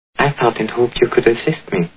The Maltese Falcon Movie Sound Bites